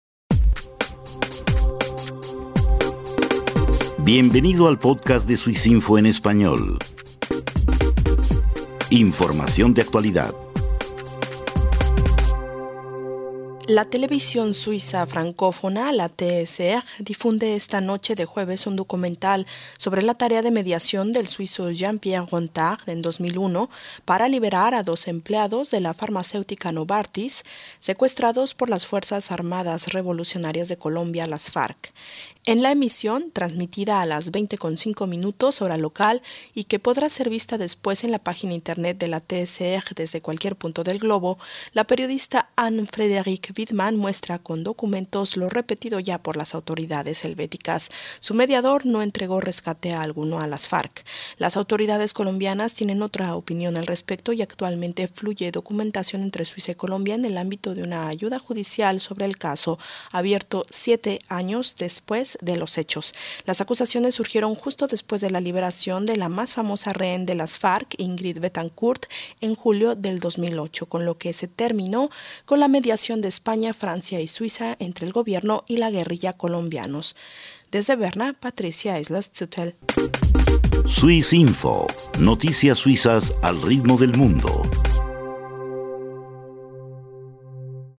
Documental sobre la acusación de colusión con las FARC de un ex mediador suizo.